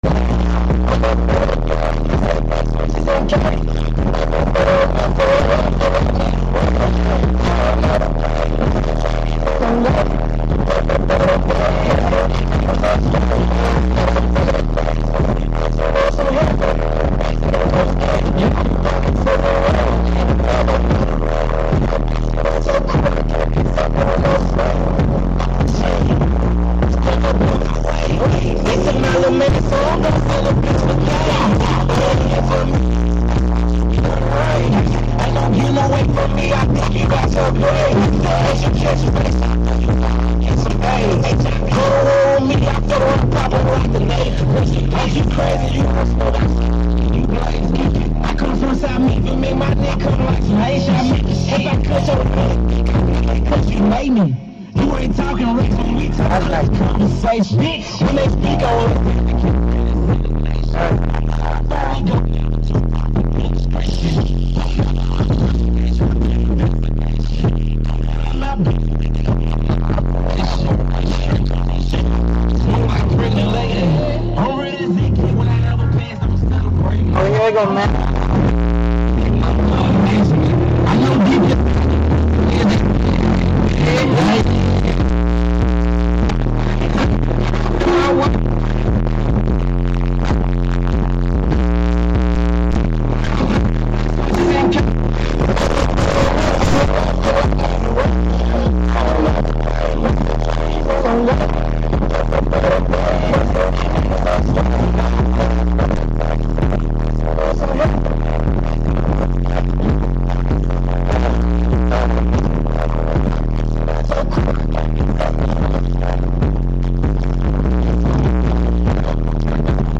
Bass testing my subwoofers, with a banger of a rap song.